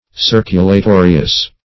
Search Result for " circulatorious" : The Collaborative International Dictionary of English v.0.48: Circulatorious \Cir`cu*la*to"ri*ous\, a. Travelling from house to house or from town to town; itinerant.
circulatorious.mp3